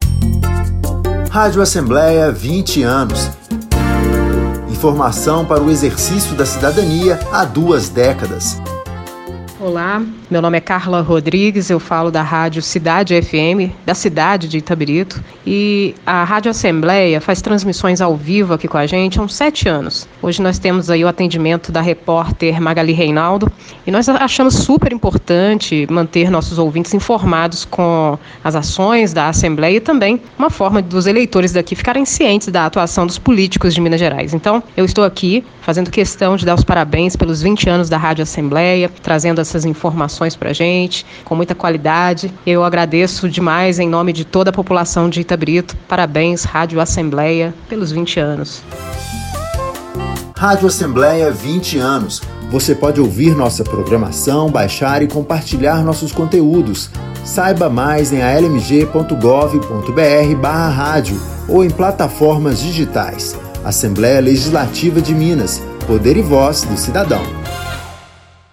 Campanhas educativas e institucionais